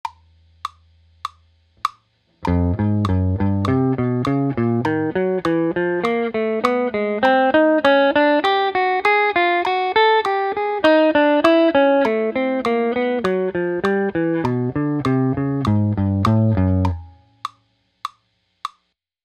This is the third of eight sets of chromatic exercises.